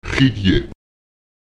td [dj] wird wie eine Kombination aus d und y artikuliert.
Lautsprecher qetde [ÈxedjE] der Sommer